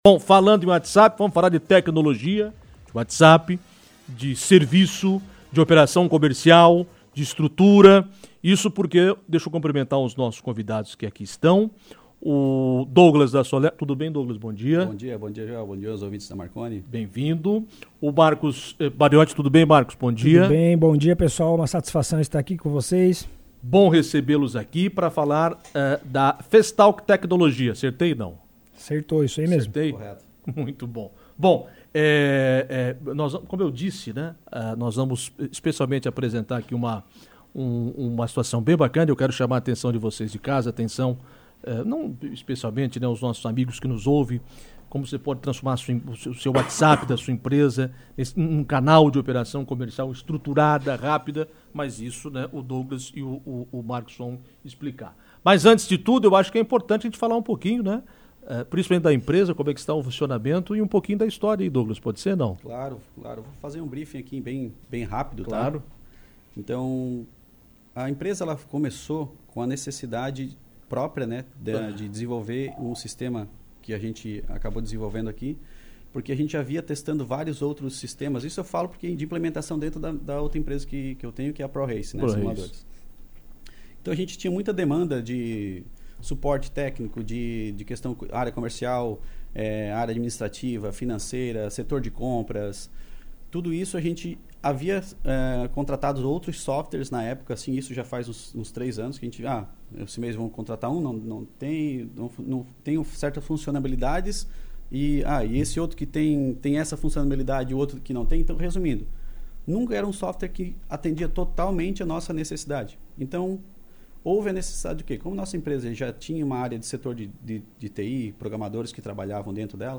O assunto foi abordado com mais detalhes em entrevista ao programa Comando Marconi.